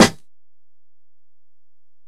Lean Wit It Snare.wav